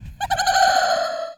laugh.wav